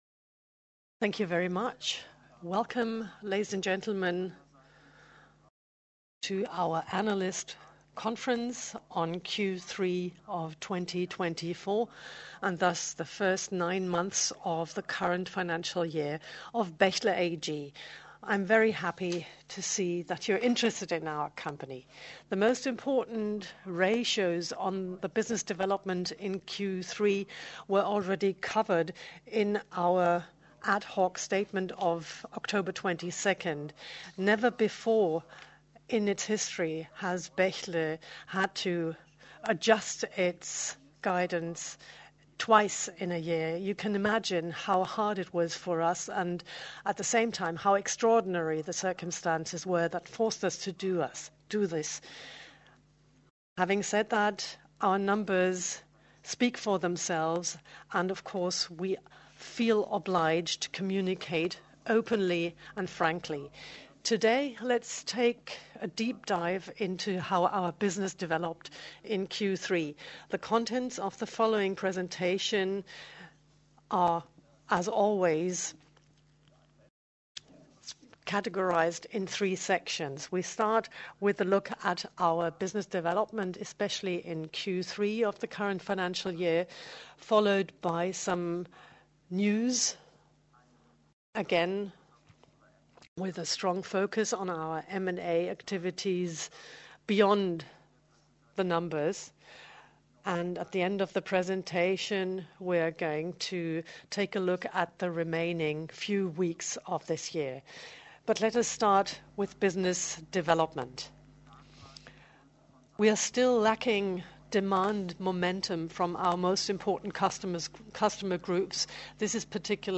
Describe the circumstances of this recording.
mitschnitt_analystenkonferenz_en_q3_2024.mp3